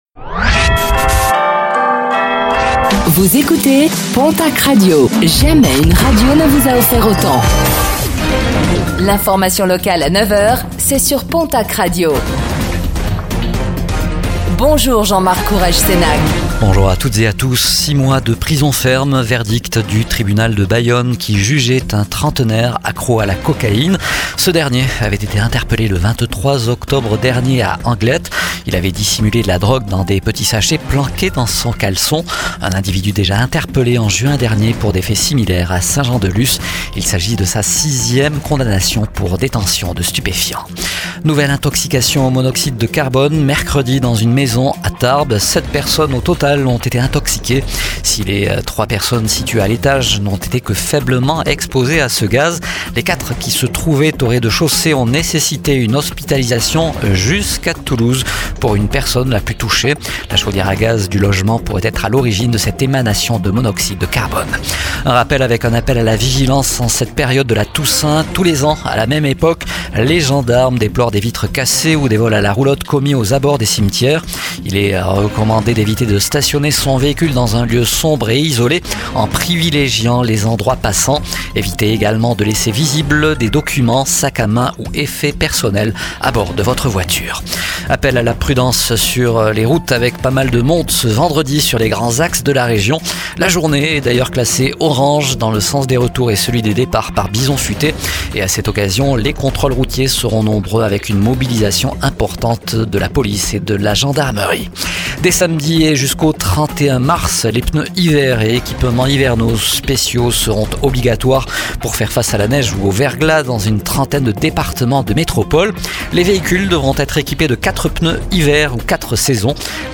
Réécoutez le flash d'information locale de ce vendredi 31 octobre 2025 ,